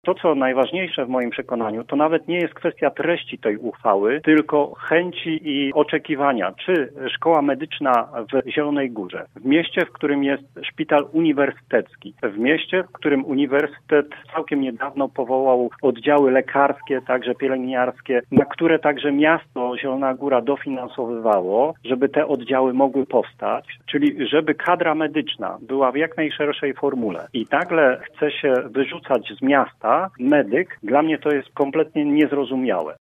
Dla mnie jest to niezrozumiałe – mówił o pomyśle przenosin Medyka przewodniczący Rady Miasta Piotr Barczak z PiS: